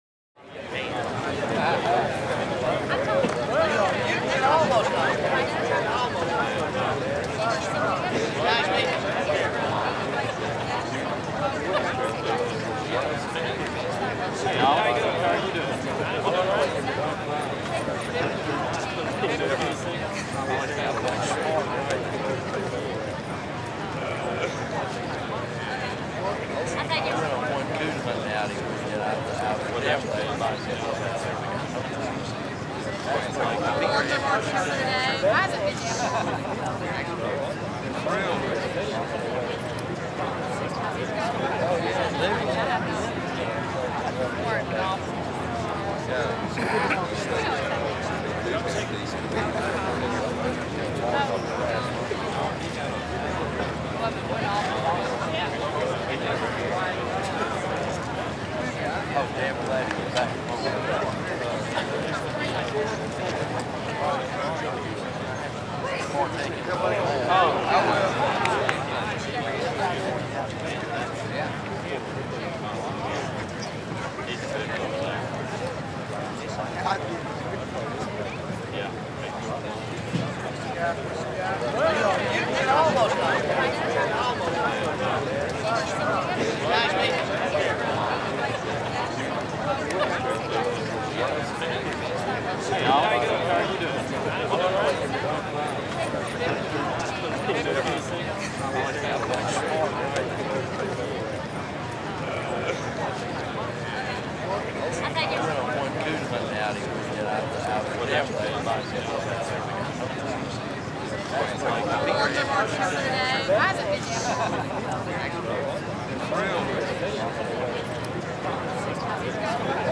human
Medium Crowd General Ambience